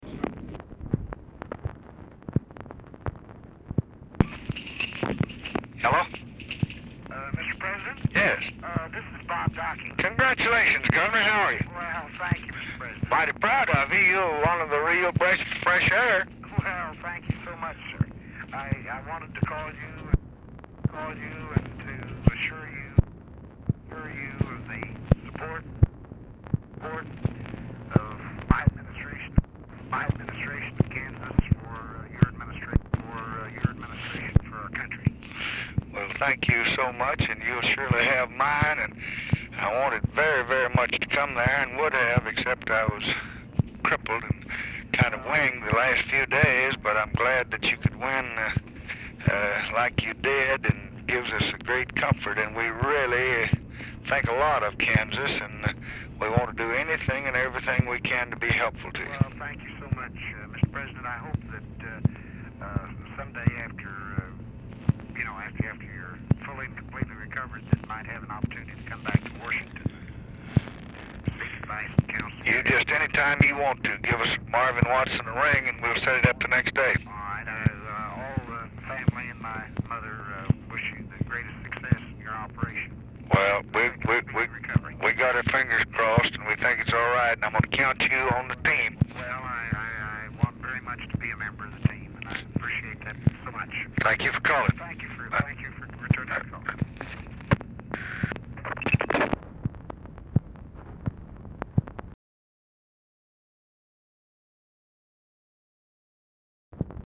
Telephone conversation
POOR SOUND QUALITY
Format Dictation belt
LBJ Ranch, near Stonewall, Texas